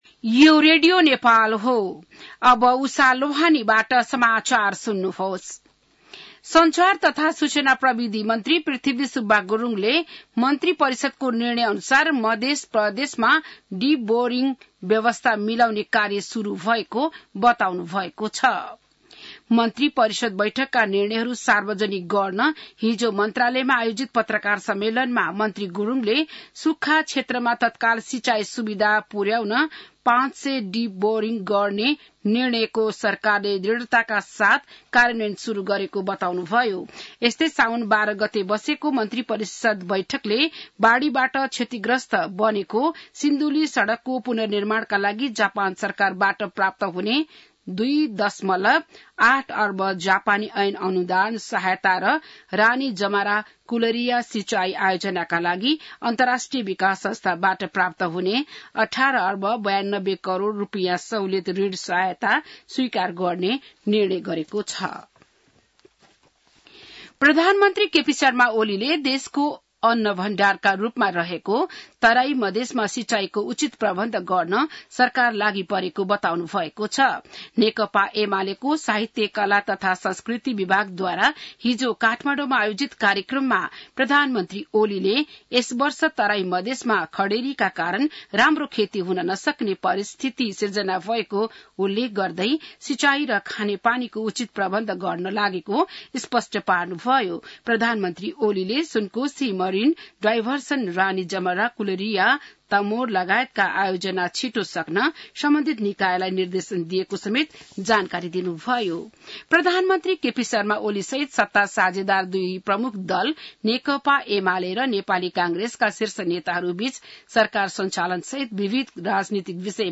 An online outlet of Nepal's national radio broadcaster
बिहान १० बजेको नेपाली समाचार : १६ साउन , २०८२